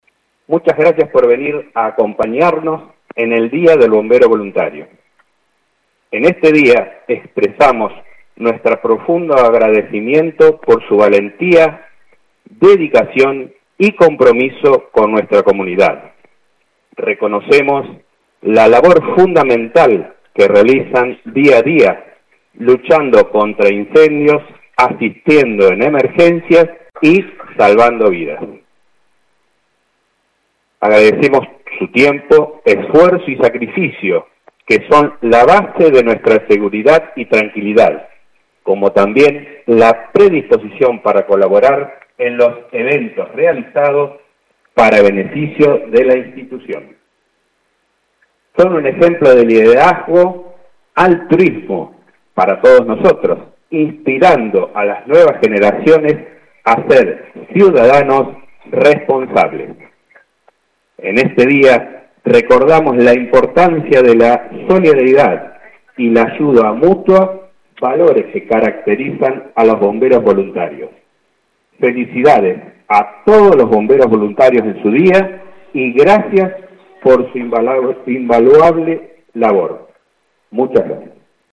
Con la presencia de la actual Comisión Directiva, integrantes del Cuerpo Activo, Cuerpo de Reserva y autoridades municipales se llevó a cabo en la mañana de hoy en el cuartel de calle Pueyrredón el acto conmemorativo por el Día del Bombero Voluntario.